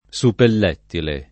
[ S uppell $ ttile ]